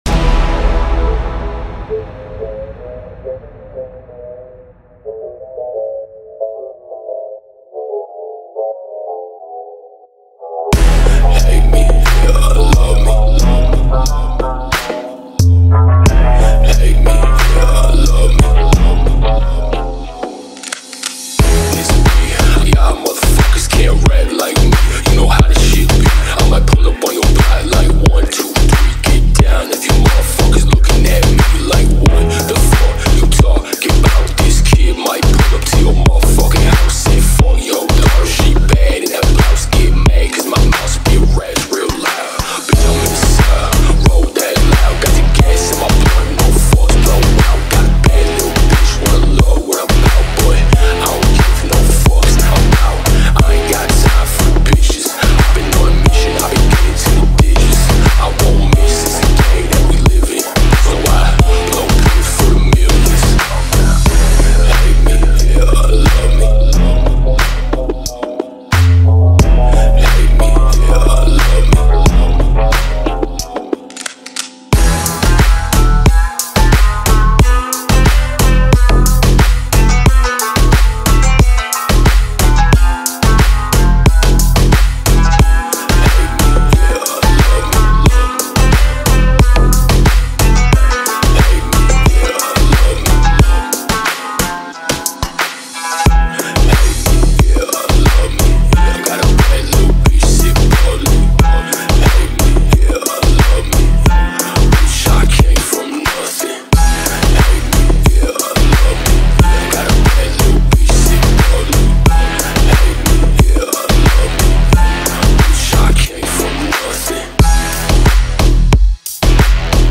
энергичная поп-музыка